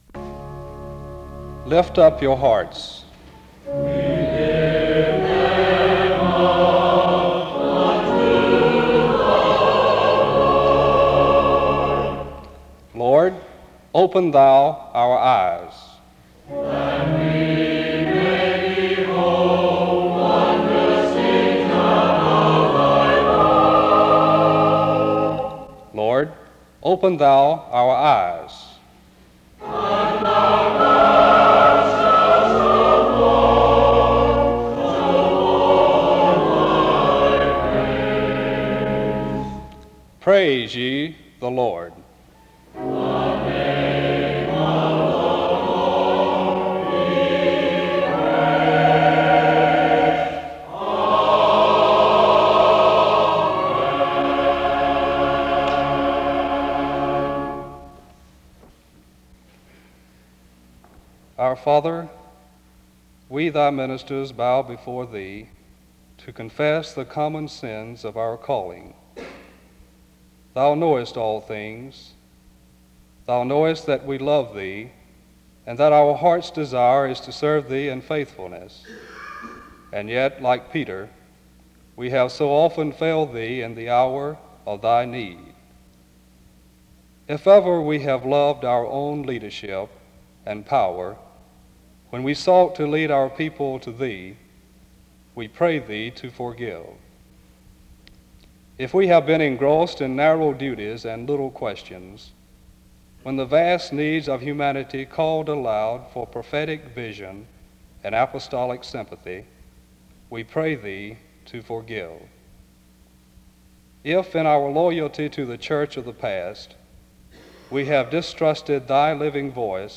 SEBTS_Chapel_Student_Day_1959-10-15.wav